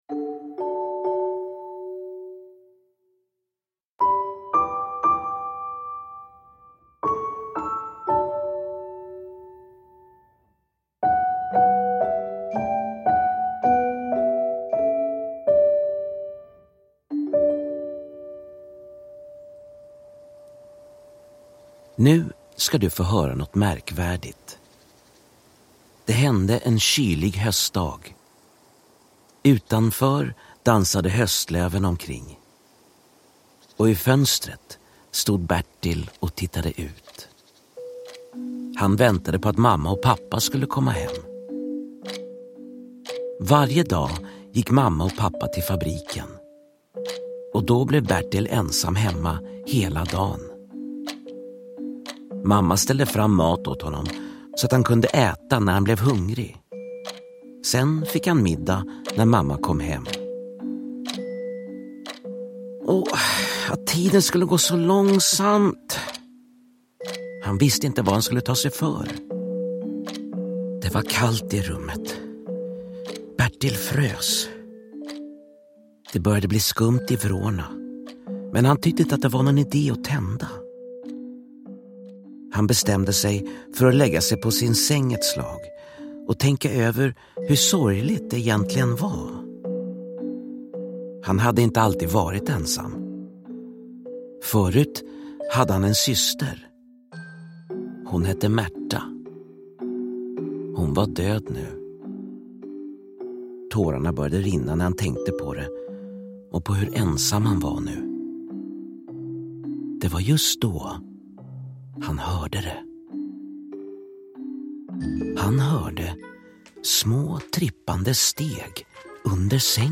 Astrid Lindgrens klassiska berättelse som ljudsaga – med musik och stämningsfulla effekter.